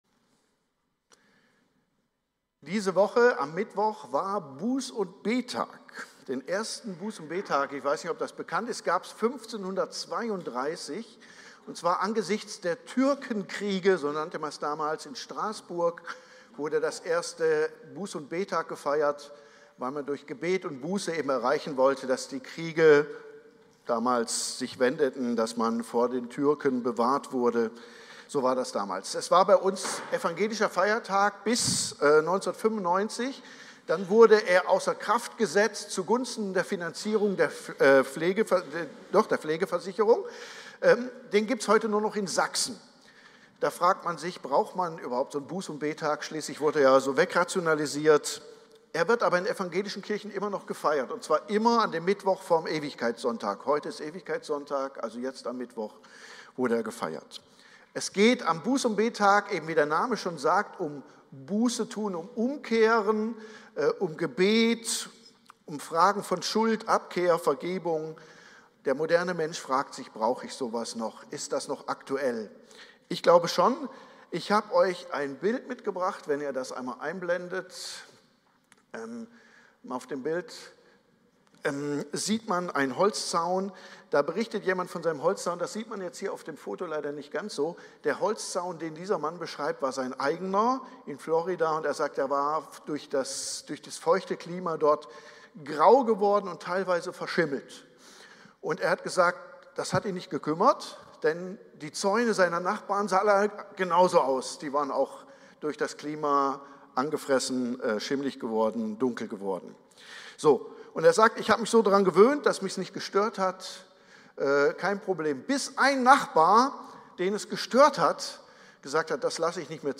Predigt-24.11-online-audio-converter.com_.mp3